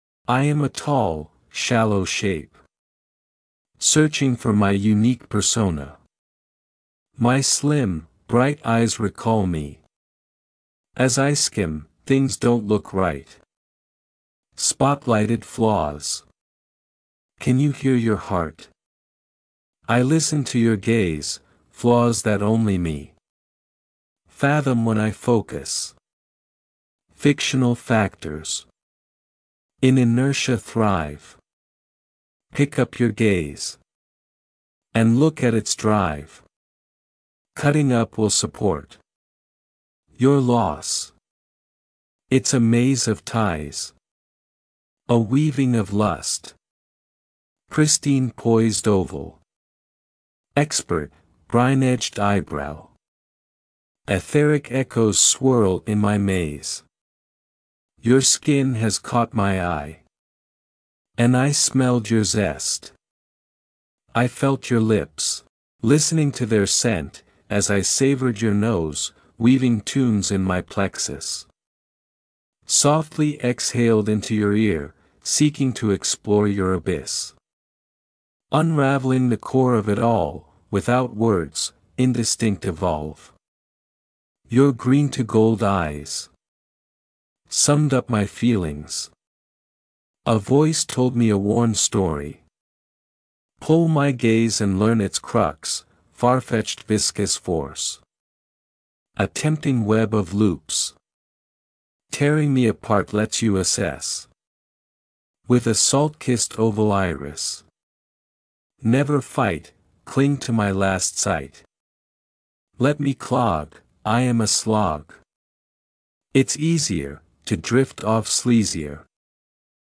BTW, the music is quite funny and encompasses parts and then the whole.